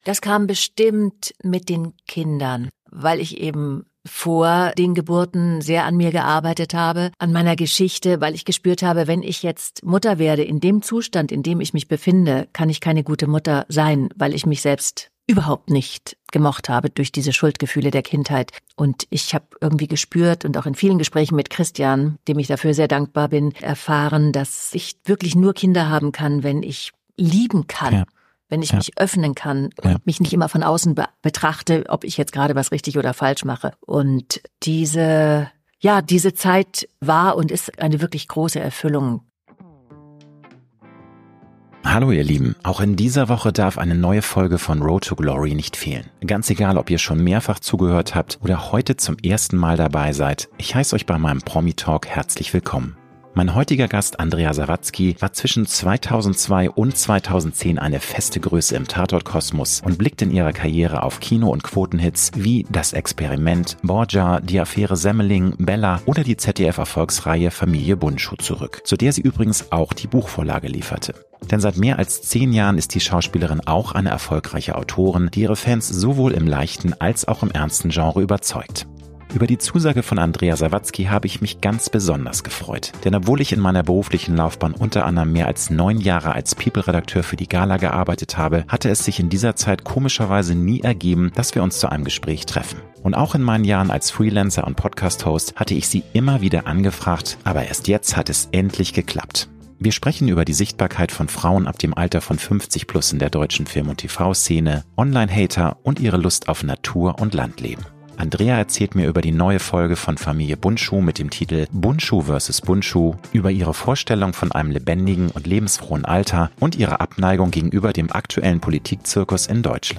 Promi-Talk